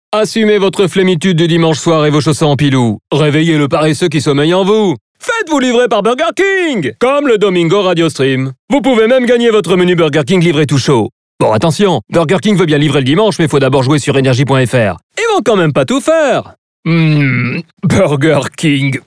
Comédien